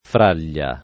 fraglia [ fr # l’l’a ]